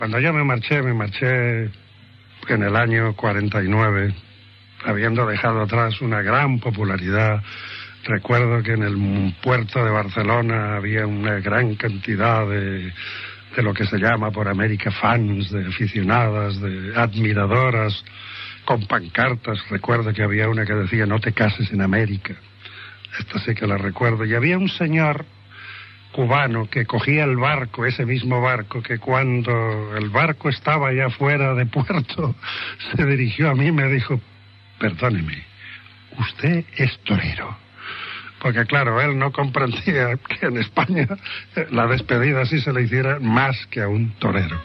L'actor Ricard Palmerola recorda quan va marxar de Barcelona cap a Amèrica, l'any 1949
Divulgació
Programa presentat per Joan Manuel Serrat